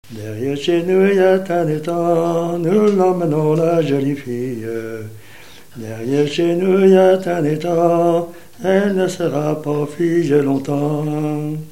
circonstance : fiançaille, noce
Genre laisse
Pièce musicale inédite